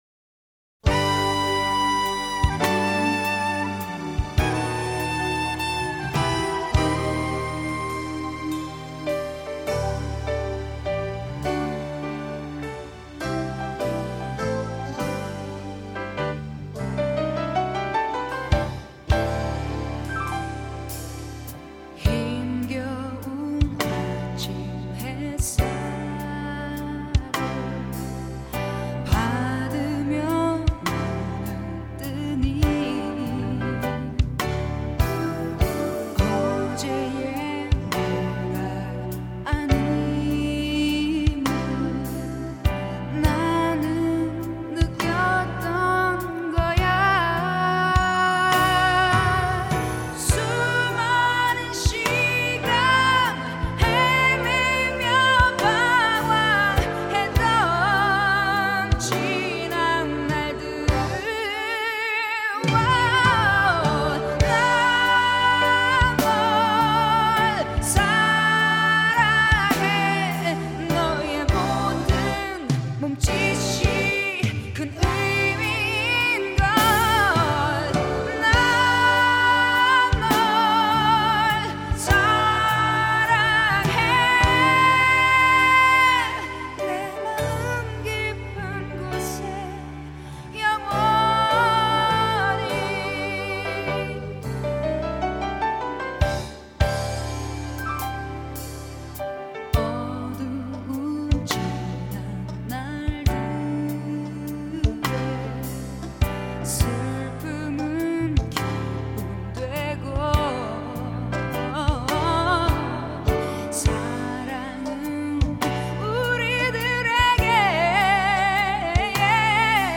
뛰어난 가창력과 미모로 한국의 휘트니휴스턴이라 불리는 가수